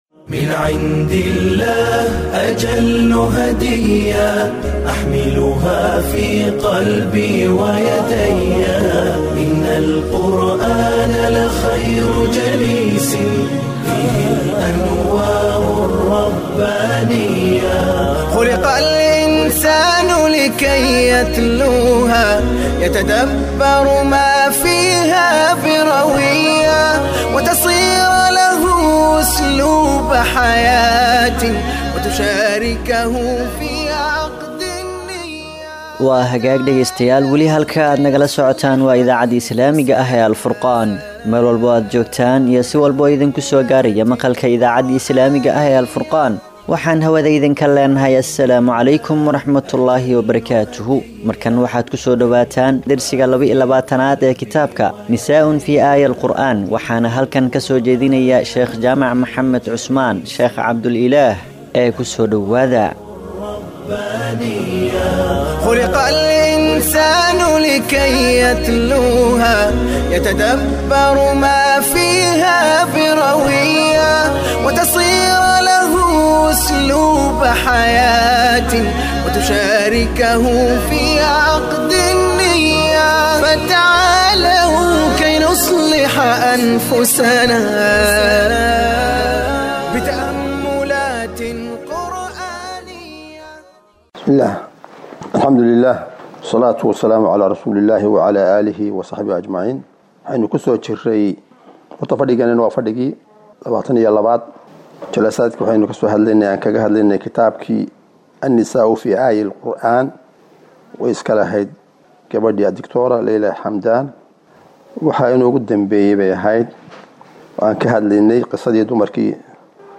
Dersiga 22aad